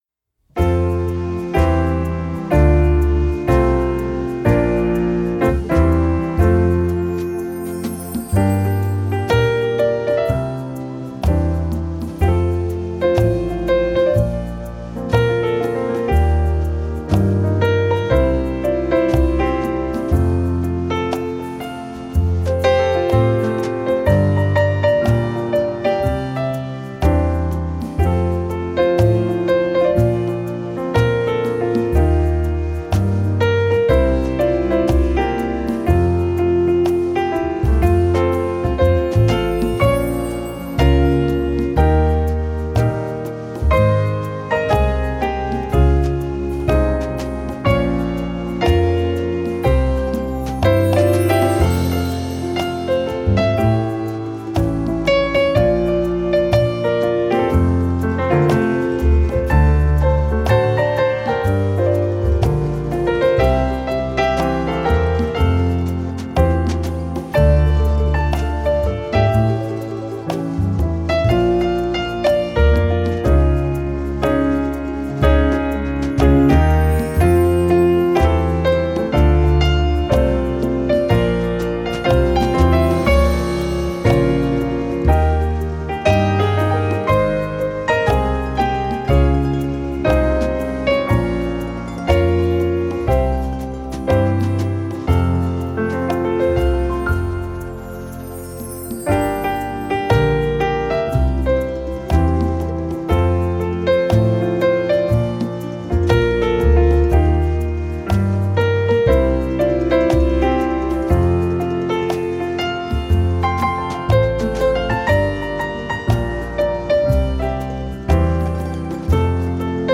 Genre: New Age, Instrumental, Piano.